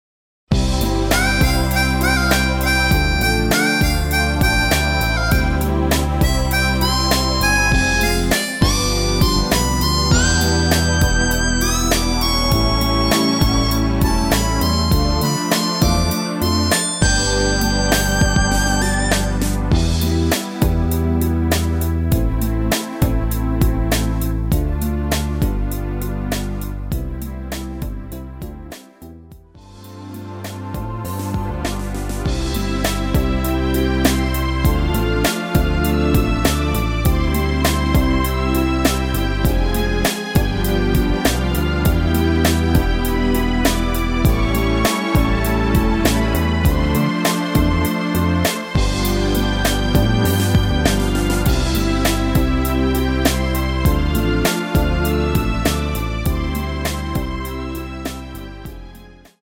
MR입니다. 엔딩이 페이드 아웃이라 엔딩을 만들어 놓았습니다. 발매일 1995 키 C 가수